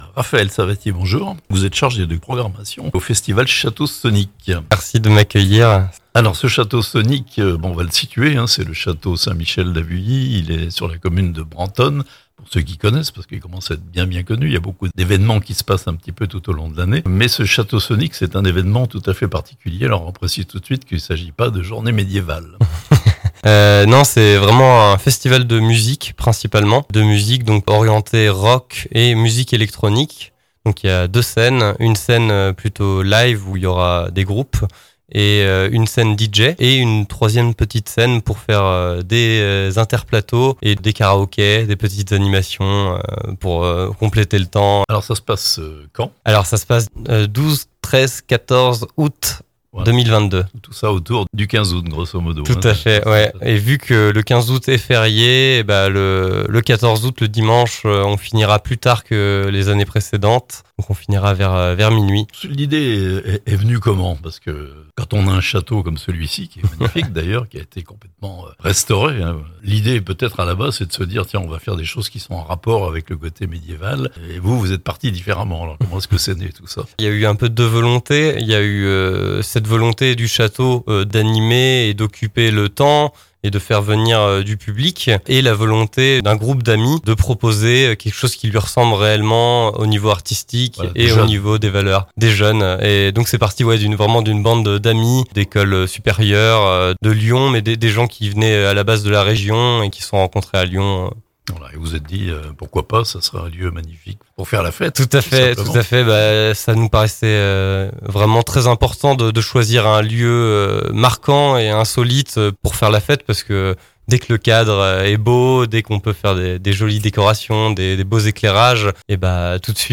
Château Sonic opus 6, le festival de musiques actuelles revient au château d'Avully, les 12, 13 et 14 août (interview)